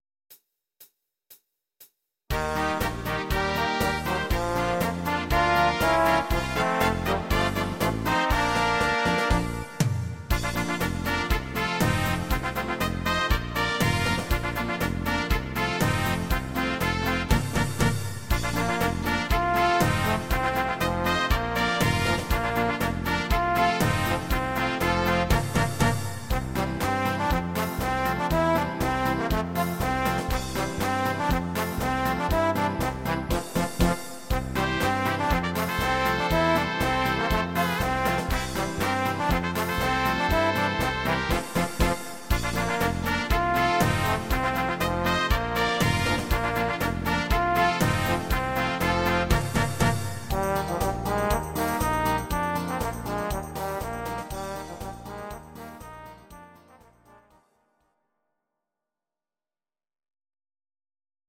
(instr.Trompete)